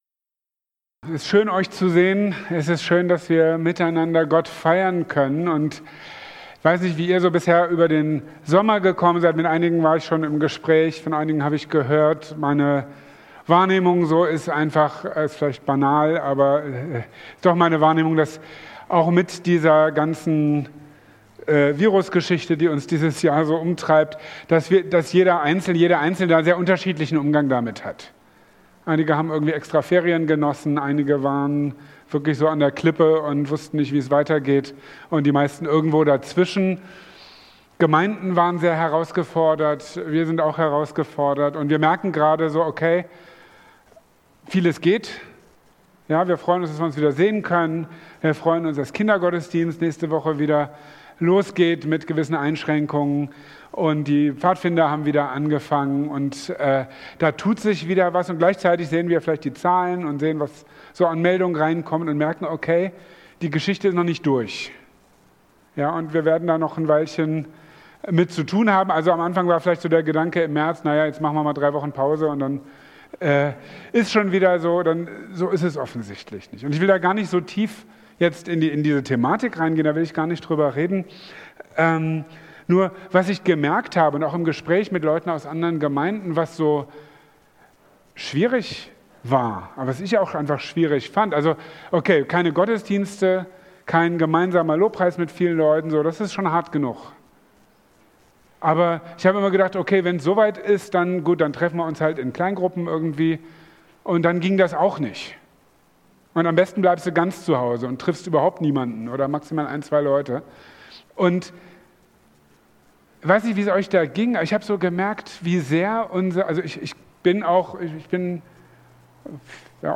In Epheser 4,1-6 mahnt Paulus uns zu einem Leben in Einheit. In seiner Predigt vom 23.